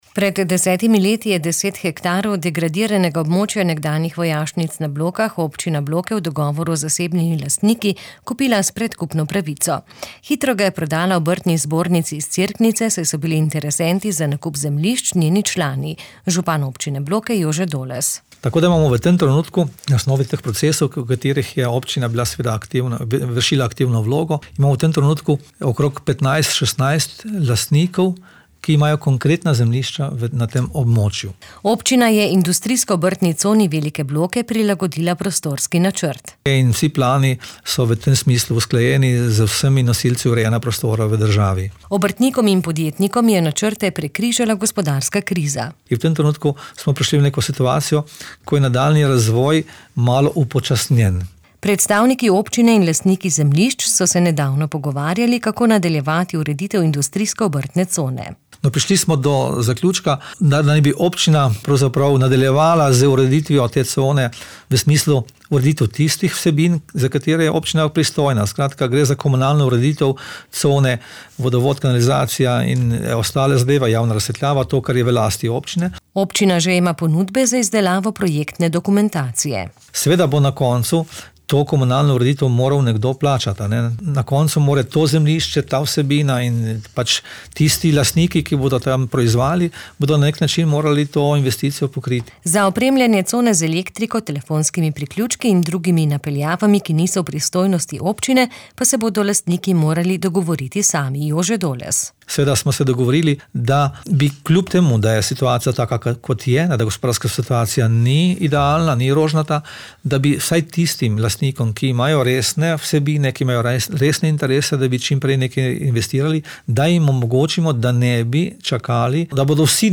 Odpiranje novih delovnih mest je tudi v interesu občine, zato bo cono komunalno uredila. Poslušaj prispevek.